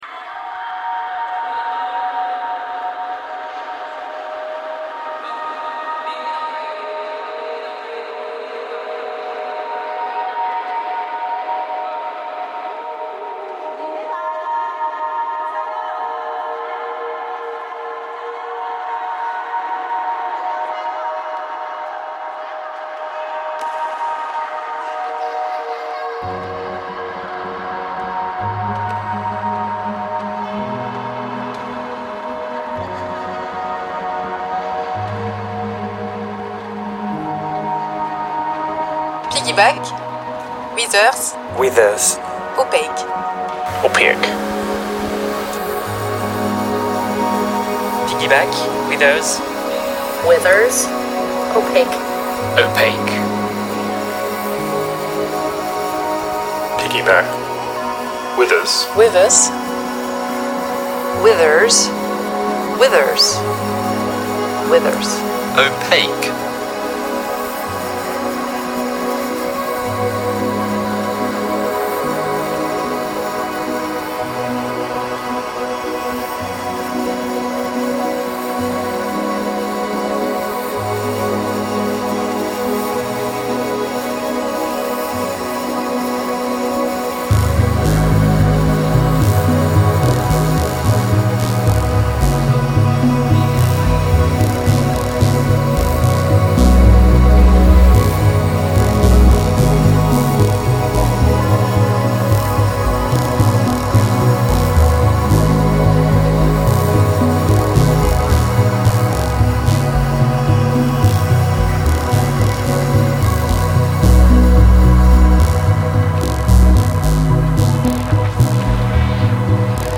I then got as many different voices as I could to record the three word co-ordinates so that they would create a kind of "tourist" multi accented voice collage that represented the myriad of echoing sounds and voices that can be heard within the original sfx and indeed the location itself."